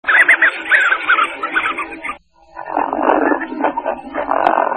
Le Fuligule Morillon (Mâle et femelle)